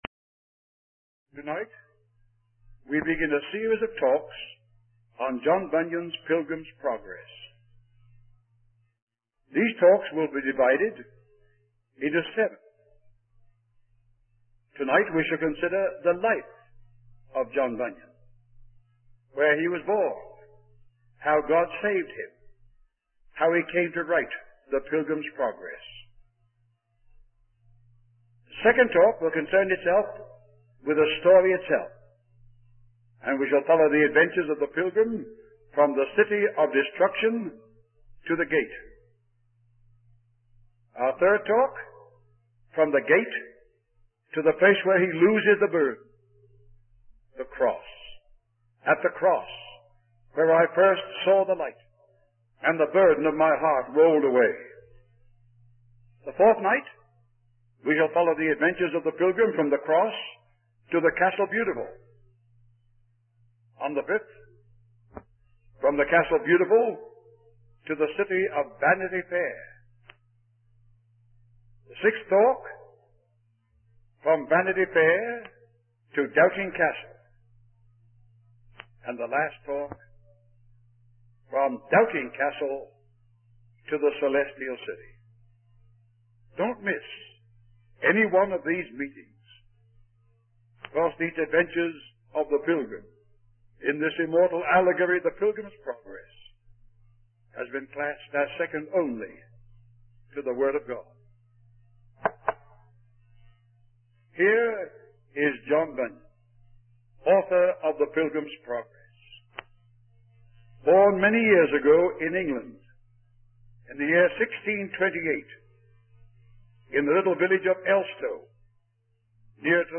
In this sermon, the preacher discusses the life and transformation of John Bunyan, the author of Pilgrim's Progress.